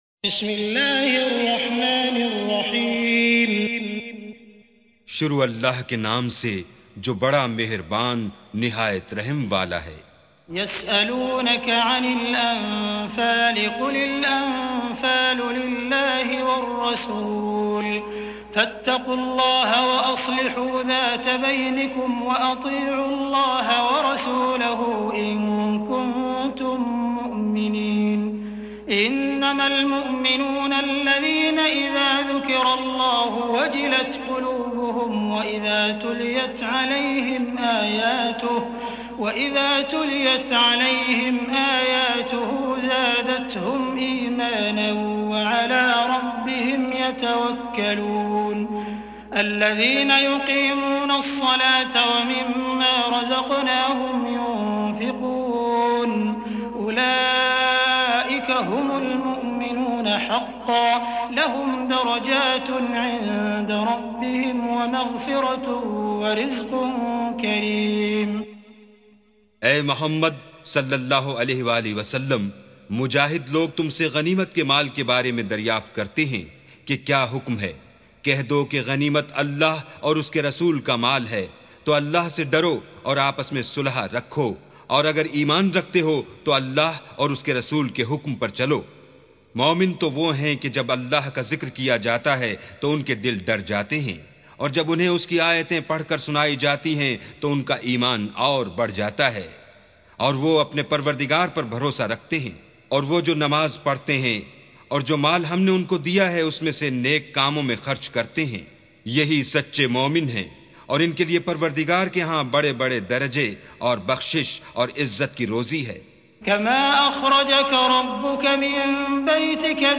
استمع أو حمل سُورَةُ الأَنفَالِ بصوت الشيخ السديس والشريم مترجم إلى الاردو بجودة عالية MP3.
سُورَةُ الأَنفَالِ بصوت الشيخ السديس والشريم مترجم إلى الاردو